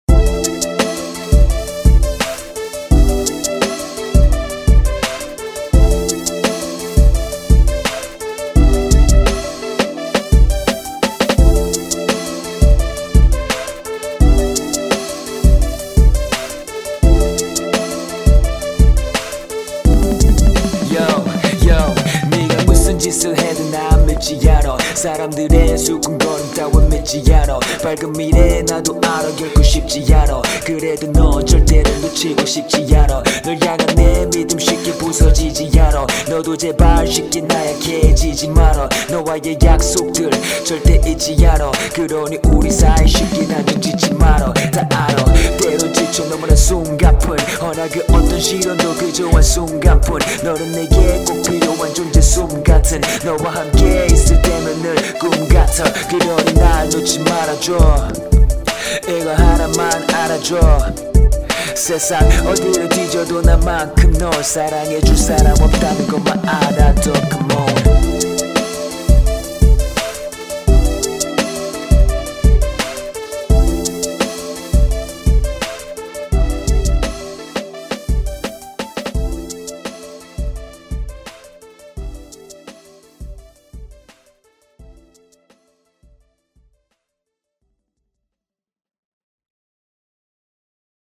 비트: 내꺼
한마디 듣자마자 '와 이사람은 목소리를 잡은 사람'이구나 싶었어요.